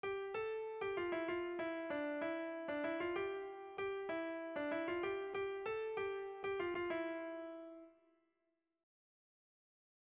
Lauko txikia (hg) / Bi puntuko txikia (ip)
AB